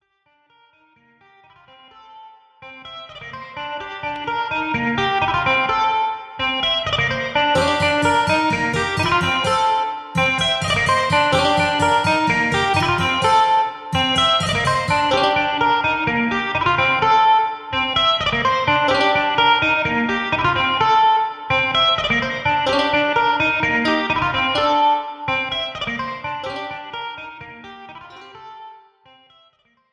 Without Backing Vocals. Professional Backing Track.
Rock